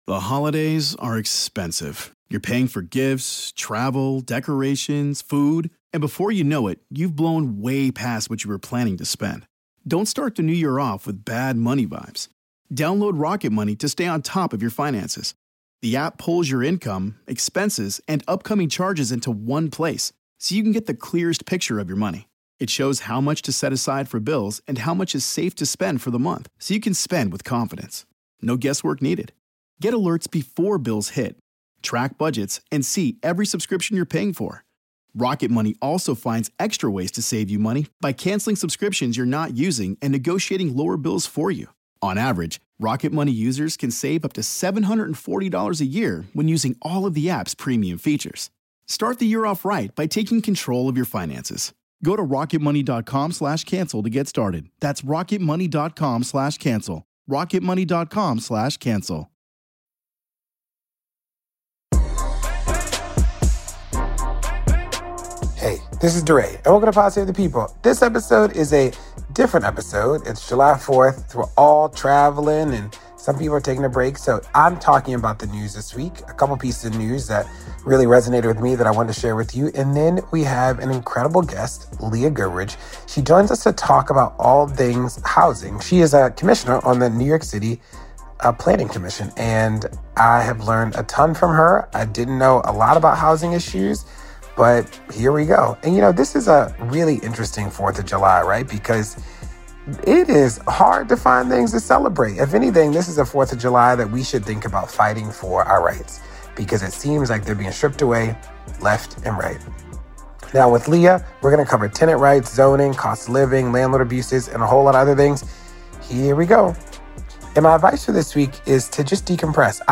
DeRay also speaks to Leah Goodridge, a Commissioner from the NYC Planning Commission and housing rights advocate.